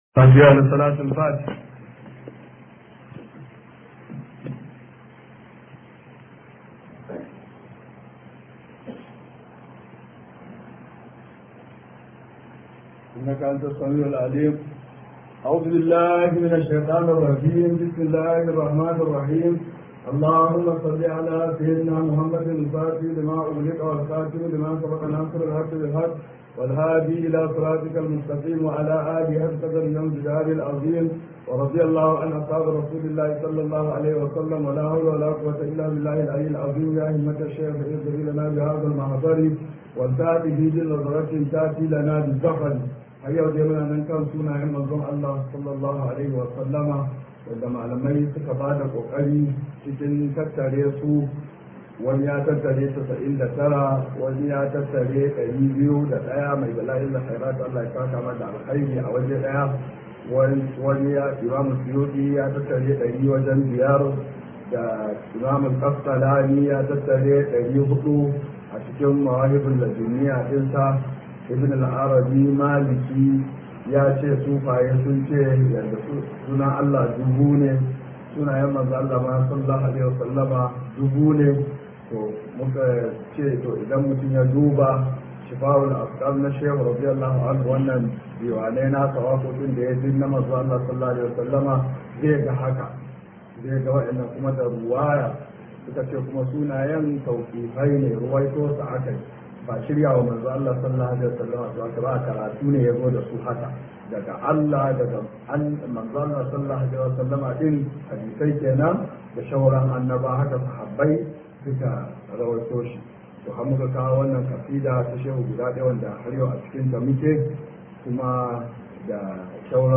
_215 TAFSIR (2019_1440).mp3